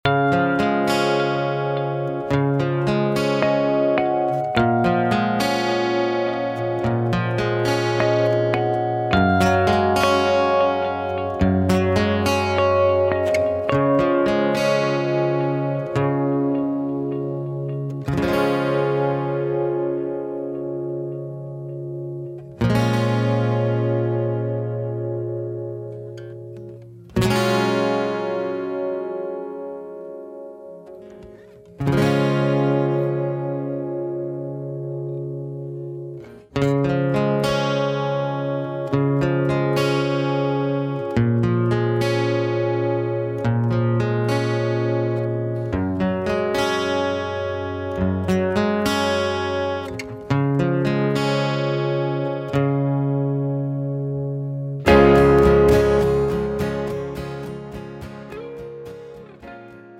장르 pop 구분 Pro MR